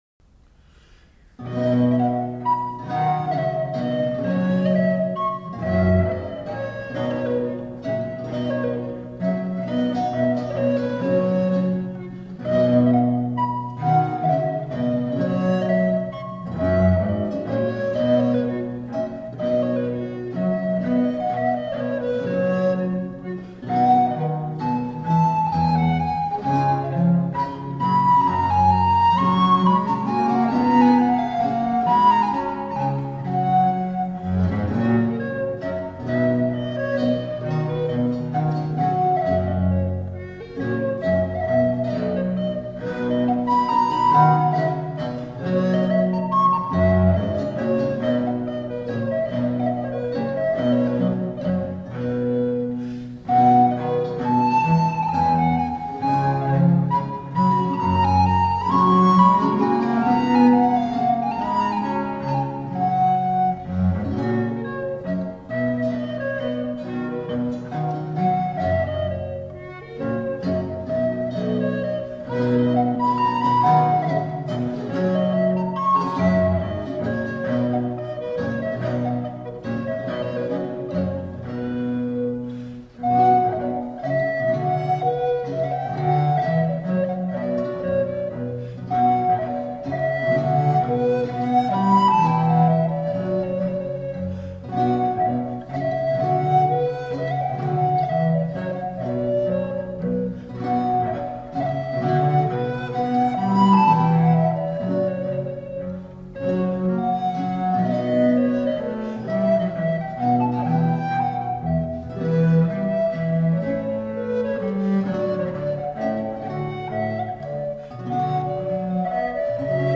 Reflective, historically-informed performance on the lute.
Recorder, Theorbo, Viola da Gamba